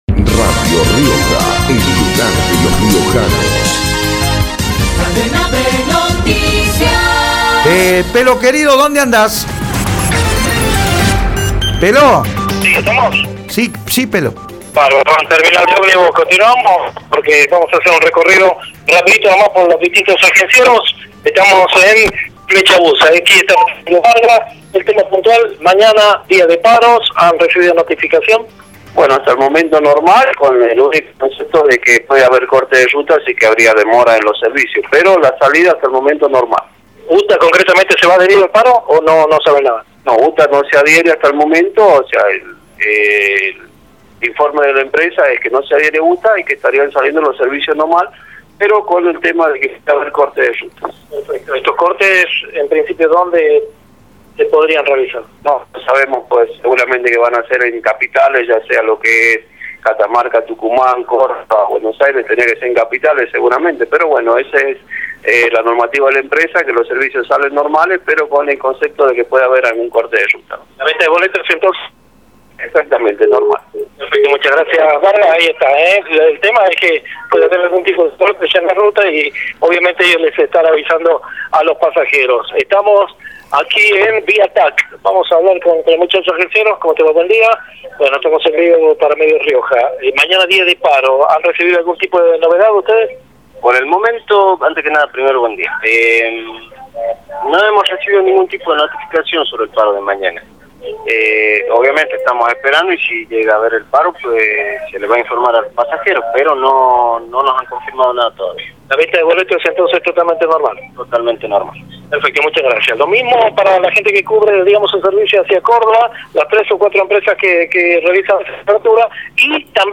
informe-de-radio-rioja-desde-la-terminal-omnibus.mp3